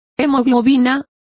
Complete with pronunciation of the translation of haemoglobins.